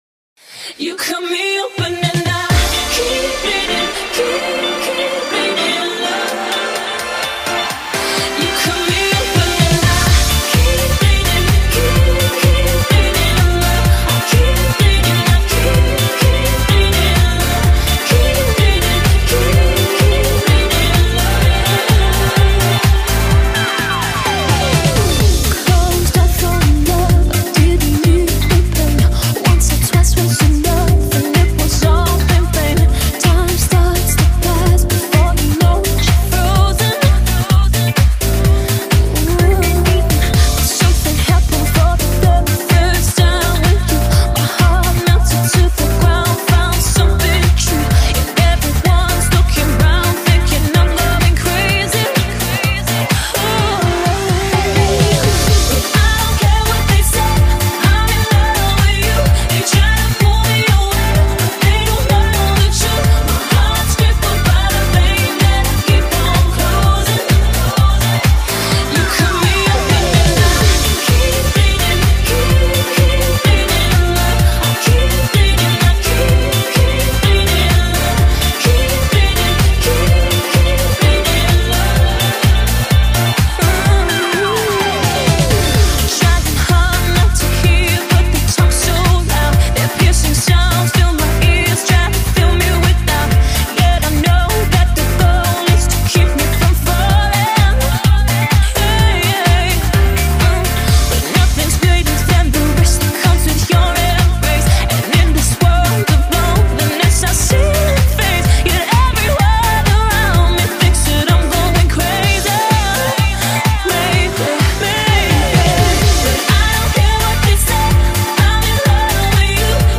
radio remix 2008